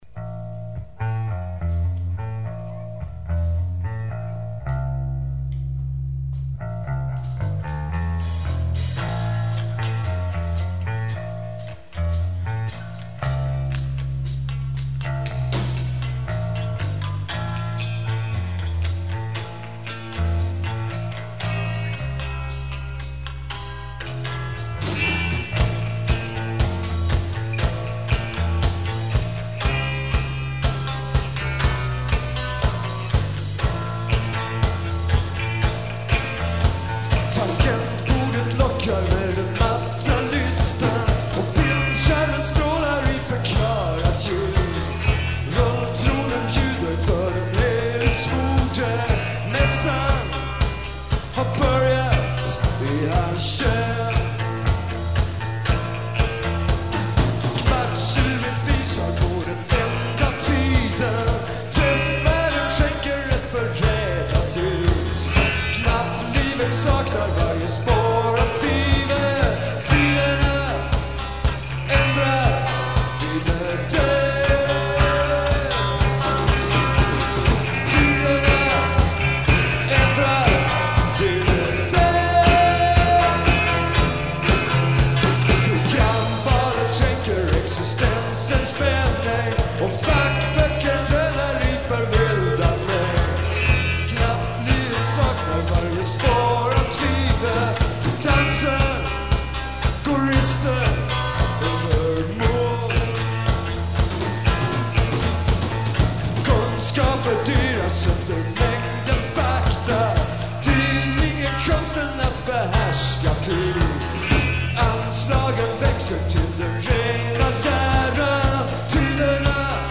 Bass
Guitar
Drums
At Arbis, November 9, 1983